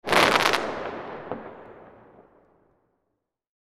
Crackling Fireworks In The Sky Sound Effect
Description: Crackling fireworks in the sky sound effect. Celebration or ceremony with fireworks in the sky – sharp, popping, sizzling, and crackling firework sounds.
Crackling-fireworks-in-the-sky-sound-effect.mp3